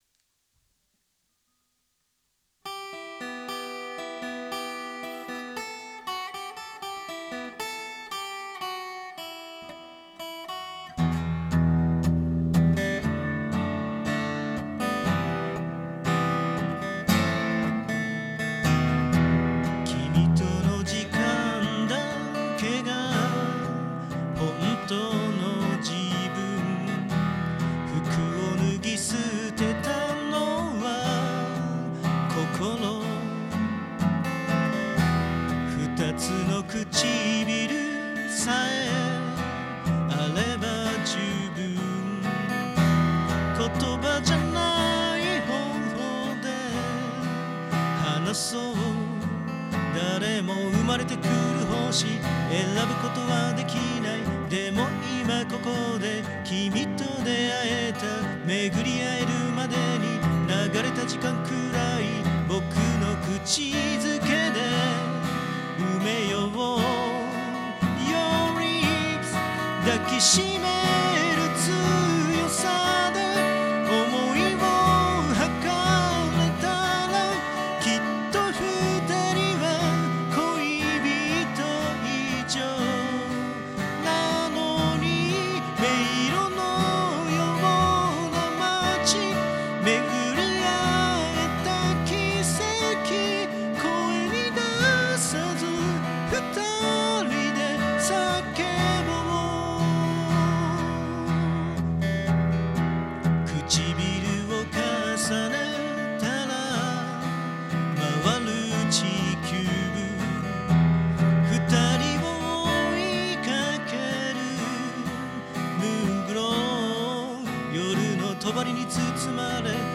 肝心のノイズは・・・ほとんど差がないですね。
まあ、歌はともかく、ギターはなかなかいい音です。